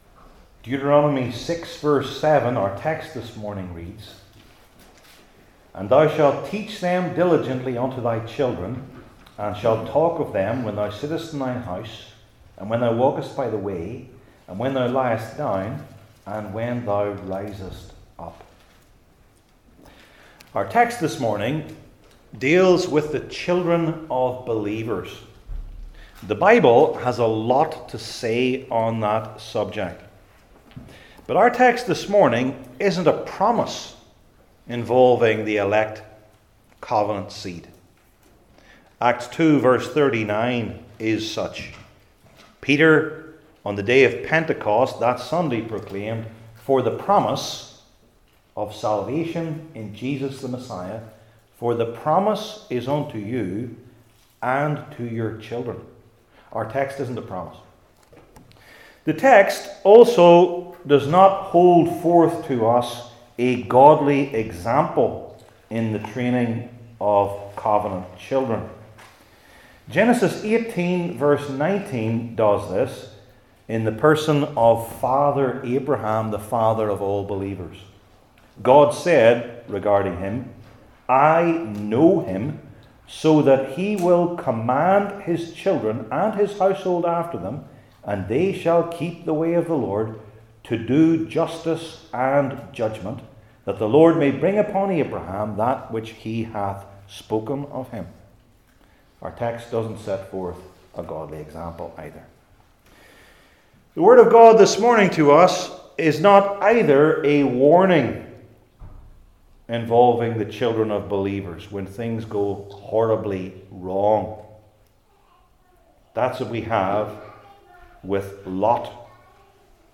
Old Testament Individual Sermons I. Who?